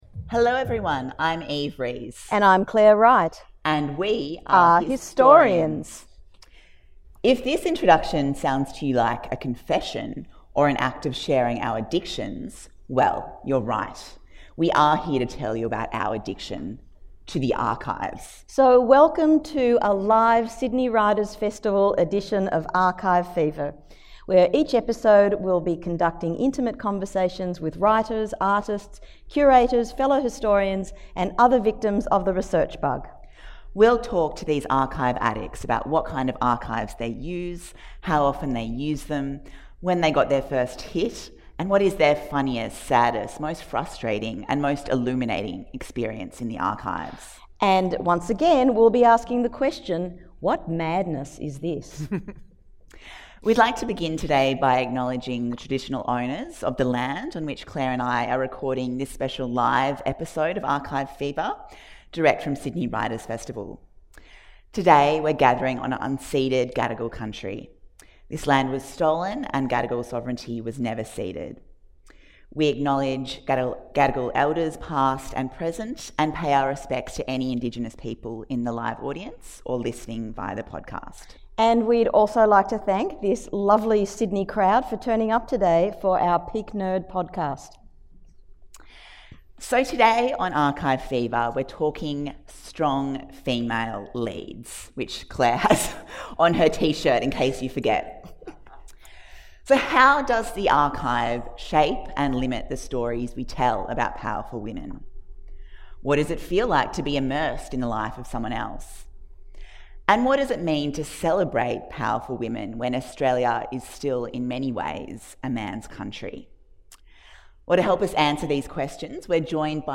26 | Strong Female Leads (Live at the Sydney Writers' Festival)